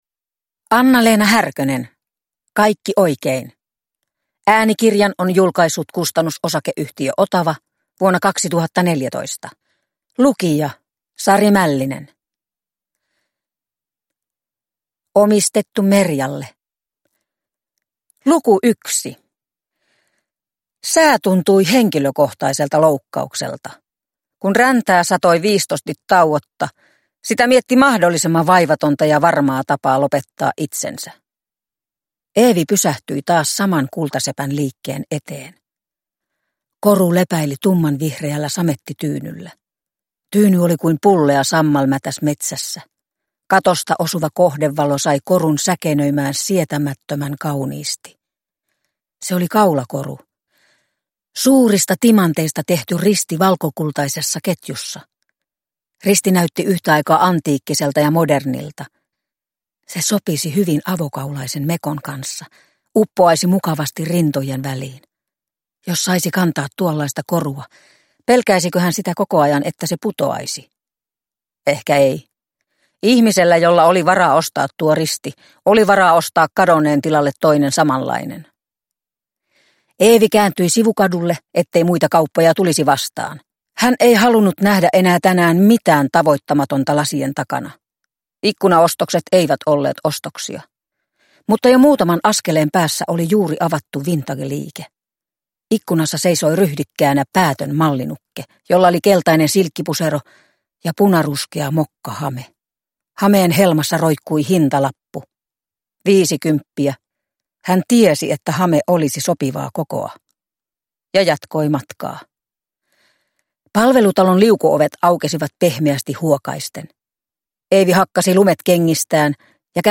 Kaikki oikein – Ljudbok – Laddas ner